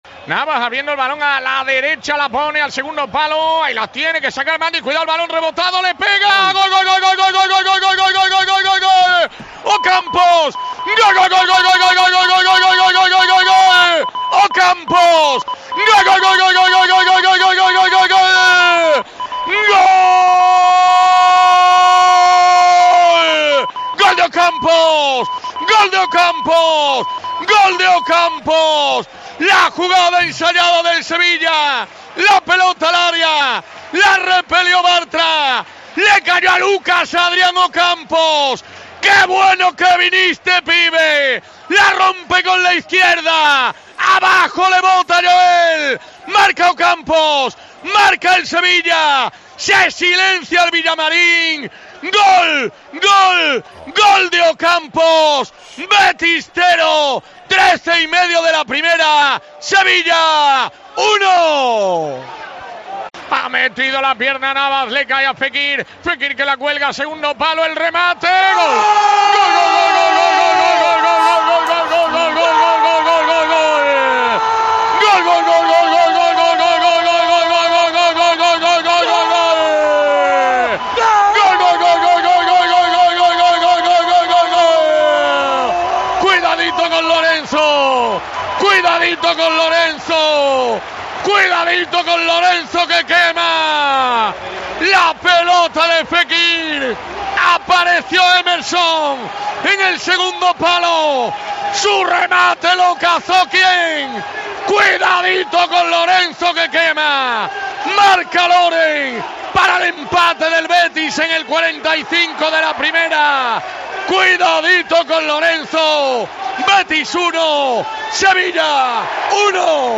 Así sonaron los goles del Real Betis-Sevilla F.C. en Cope Más Sevilla 105.8FM